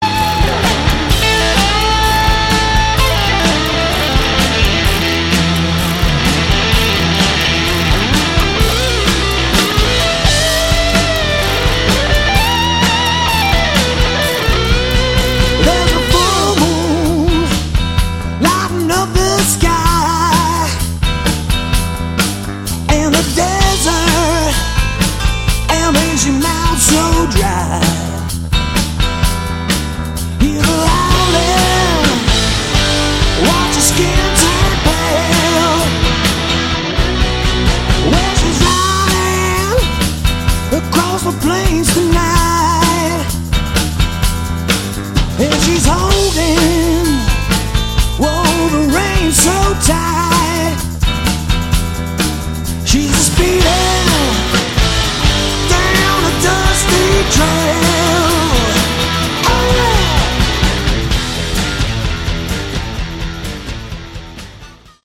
Category: Hard Rock
lead vocals, harp
guitars, vocals
guitar, mandolin
bass, vocals
drums, percussion